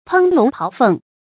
烹龍庖鳳 注音： ㄆㄥ ㄌㄨㄙˊ ㄆㄠˊ ㄈㄥˋ 讀音讀法： 意思解釋： 見「烹龍炮鳳」。